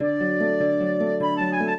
flute-harp
minuet0-5.wav